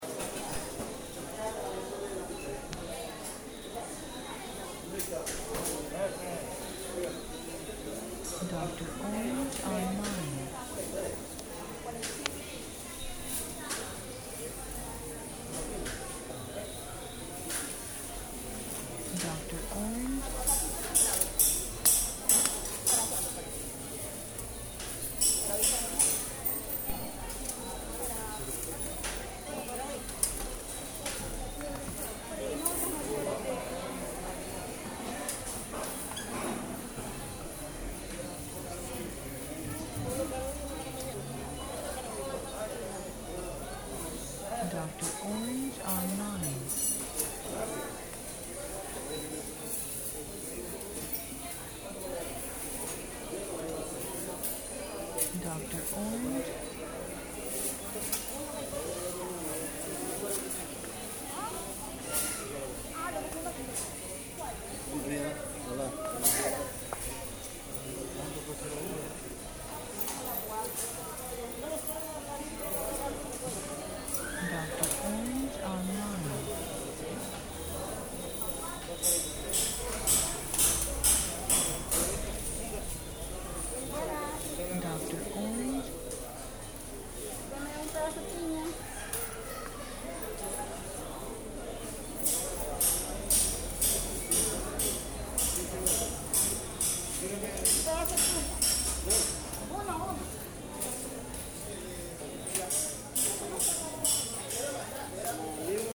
Ambiente Mono Interior Mercado de Víveres en Acción
INTERIOR MERCADO DE VIVERES, GENTE INTERACTUANDO, COMPRANDO, PRECIOS Y VENTA (3min52seg).
Archivo de audio ESTÉREO, 96Khz – 24 Bits, WAV.
INTERIOR-MERCADO-DE-VIVERES_AMBIENTE-96KHZ.mp3